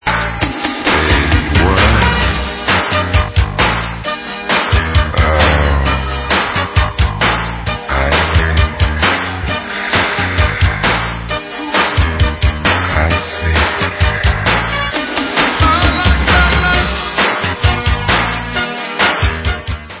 sledovat novinky v oddělení Dance/Soul